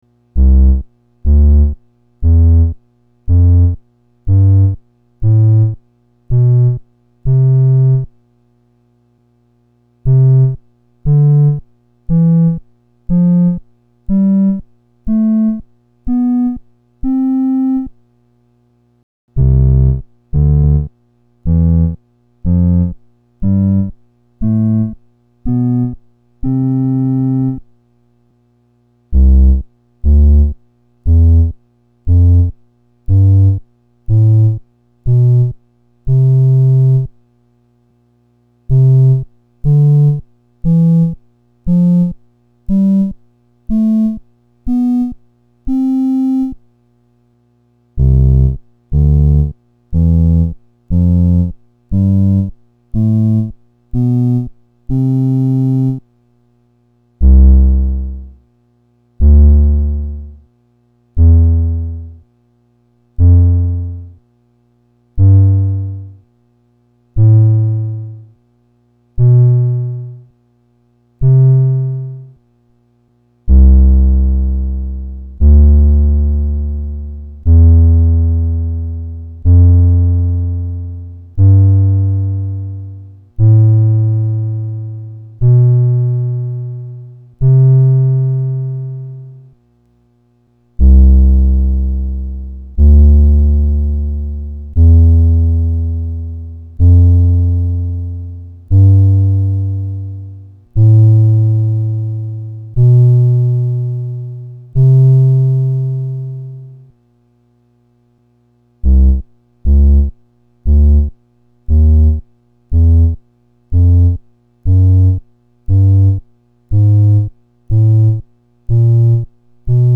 EKO_Synth_sample.mp3